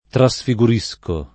trasfiguro [ tra S fi g2 ro ]